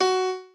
b_piano1_v100l4o5fp.ogg